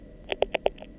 The present paper analyzes the sounds emitted by pre-hatching chicks, focusing on those named as “clicks,” which are thought to mediate pre-hatching social interactions and hatching synchronization.
As hatching approaches, clicks evolve from isolated events to highly organized hierarchical clusters.